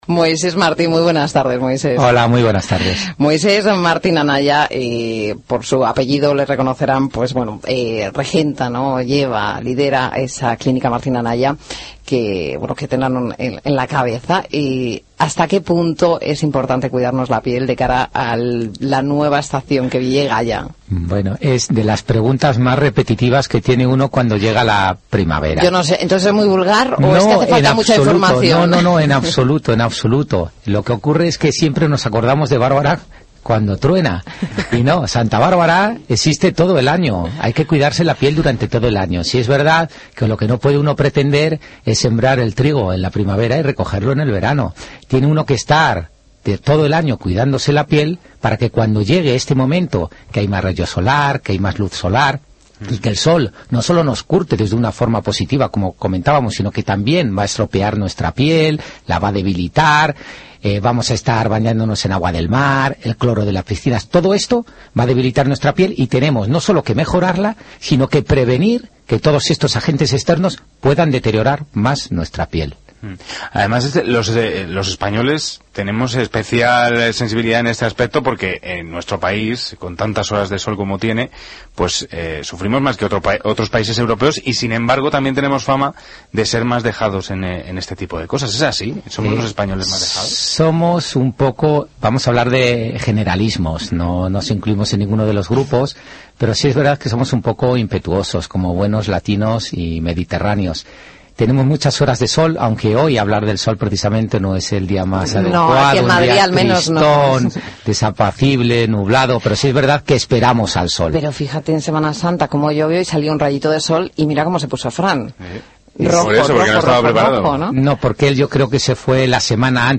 Entrevista en Gestiona Radio
Entrevista-Gestiona-Radio-Edicion-Limitada.mp3